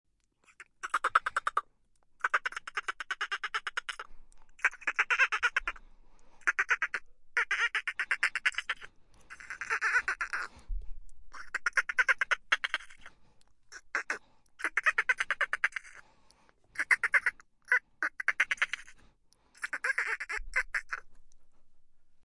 Download Dolphin sound effect for free.
Dolphin